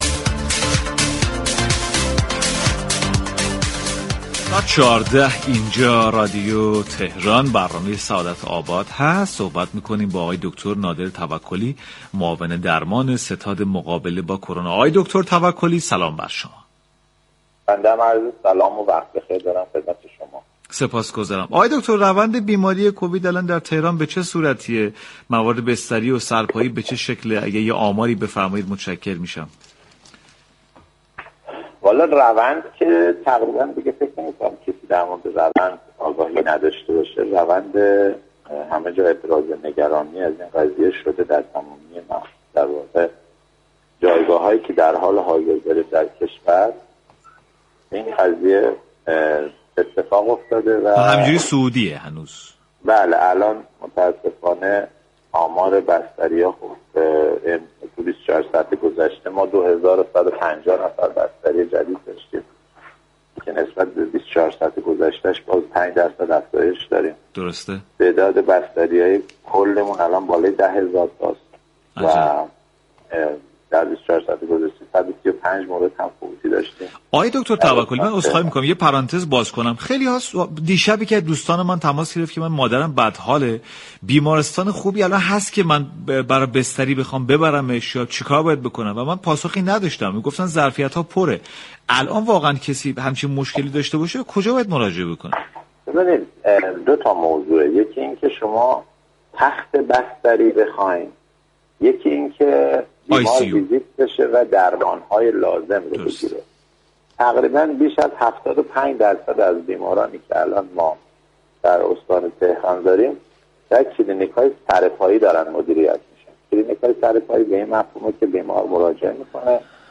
به گزارش پایگاه اطلاع رسانی رادیو تهران، دكتر نادر توكلی معاون درمان ستاد مقابله با كرونای استان تهران در گفتگو با برنامه سعادت آباد رادیو تهران ضمن ابراز نگرانی از وضعیت كرونا در كشور گفت: متاسفانه آمار بستری ها صعودی است.